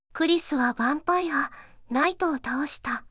voicevox-voice-corpus
voicevox-voice-corpus / ita-corpus /四国めたん_セクシー /EMOTION100_009.wav